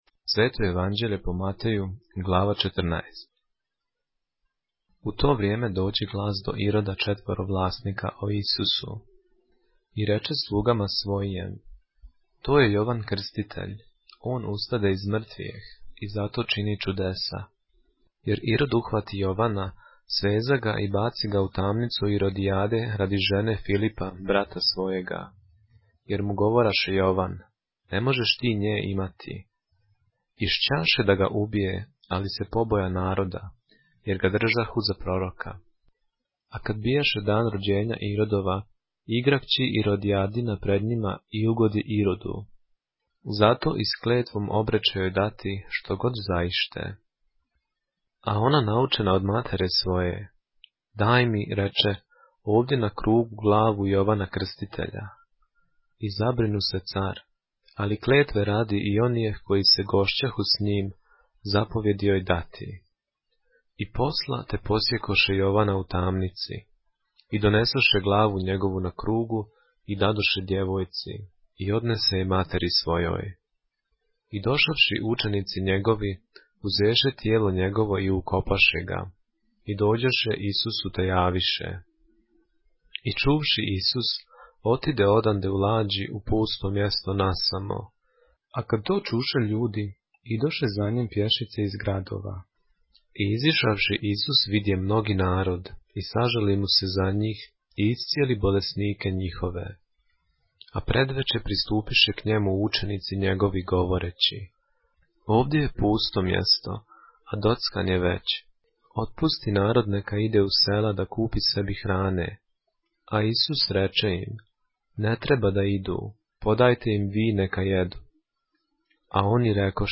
поглавље српске Библије - са аудио нарације - Matthew, chapter 14 of the Holy Bible in the Serbian language